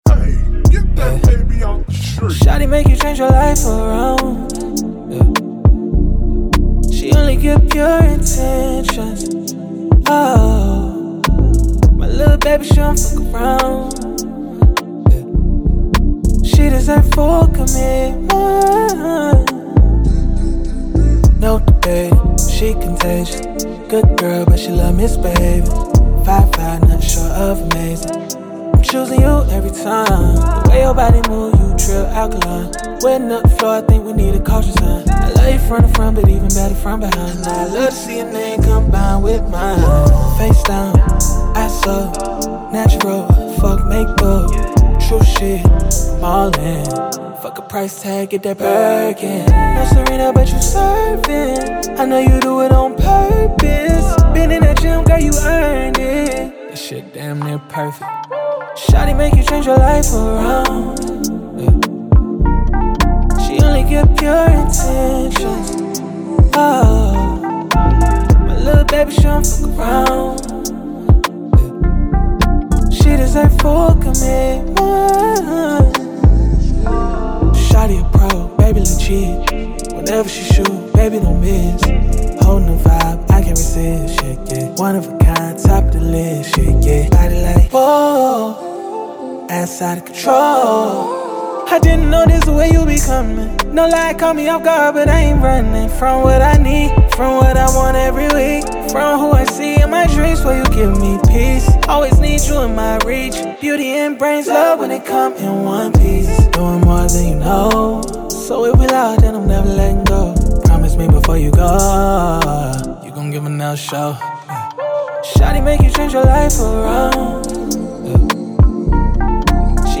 R&B
D Minor